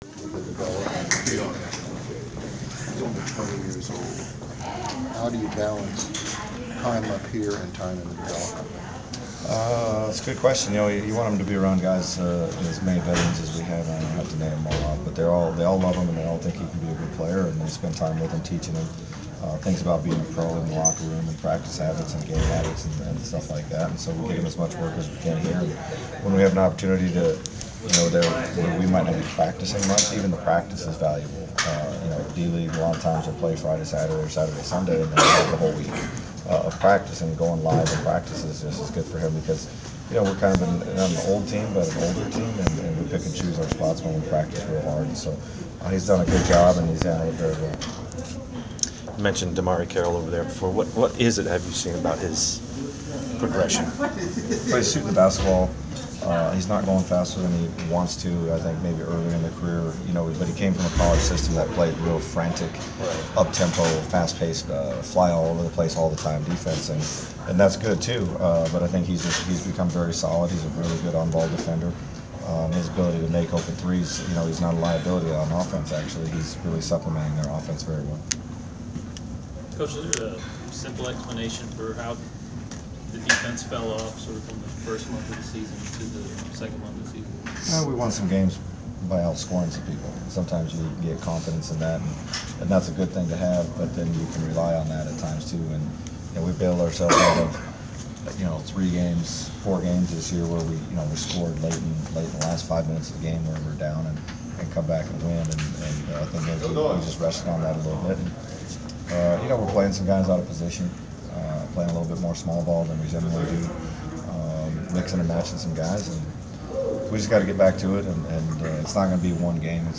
Inside the Inquirer: Pregame presser with Memphis Grizzlies’ head coach David Joerger (1/7/15)
We attended the pregame presser of Memphis Grizzlies’ head coach David Joerger before his team’s road contest against the Atlanta Hawks on Jan. 7. Topics included playing on the road and the play of Hawks’ forward DeMarre Carroll.